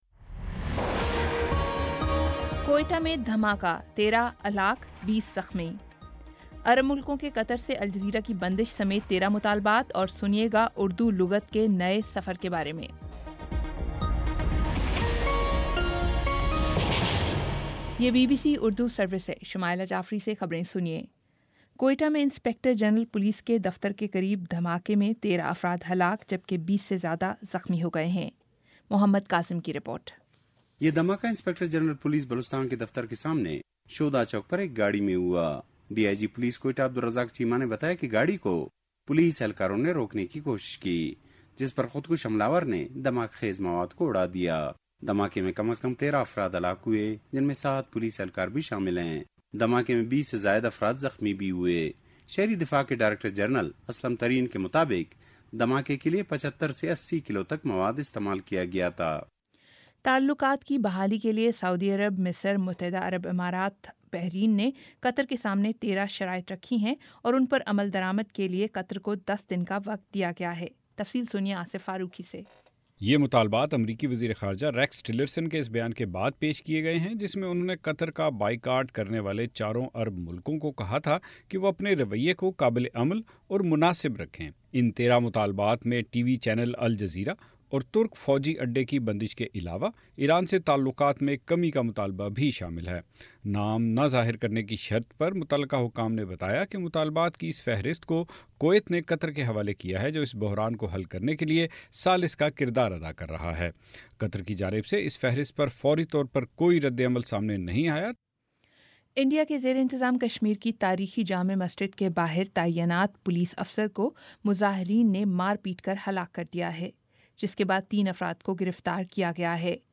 جون 23 : شام چھ بجے کا نیوز بُلیٹن